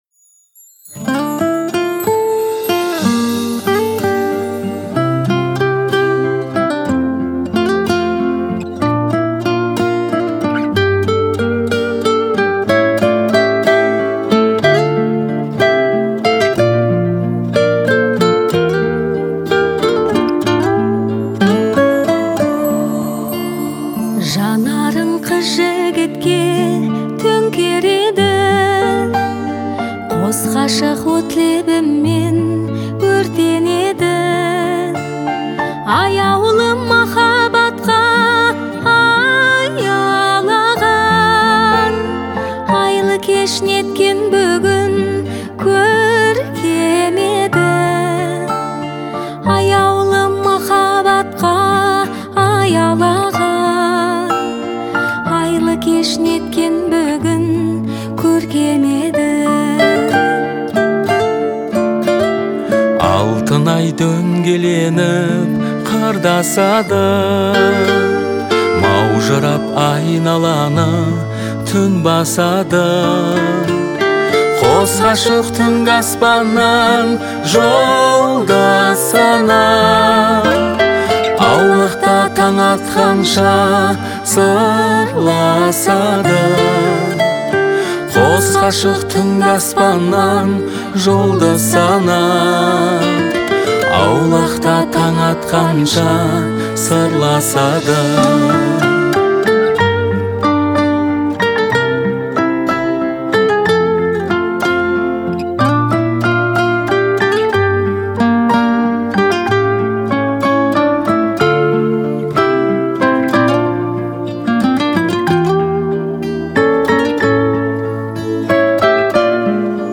это яркий представитель казахской поп-музыки